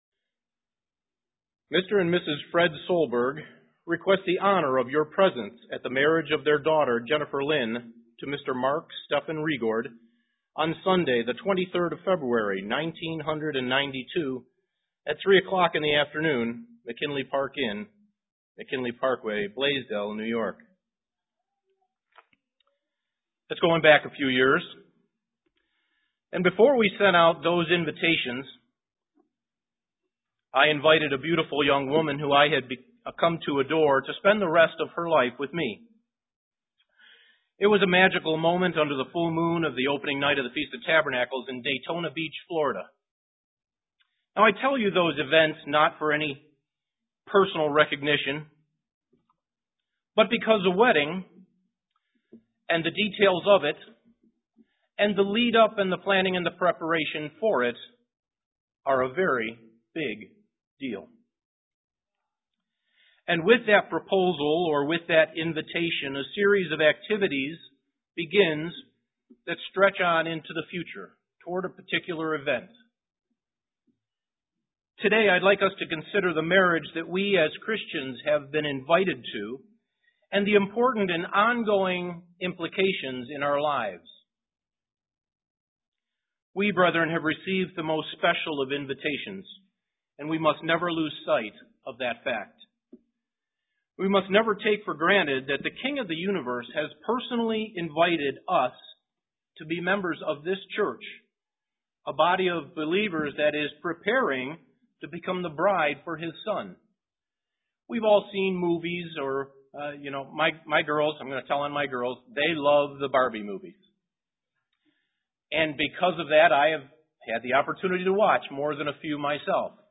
Print Our calling is similar to a wedding invitation UCG Sermon Studying the bible?